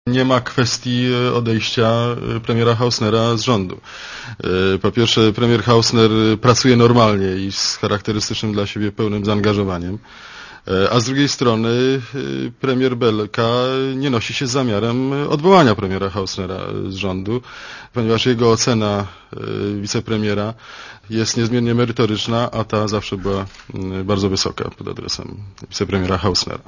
Mówi rzecznik rządu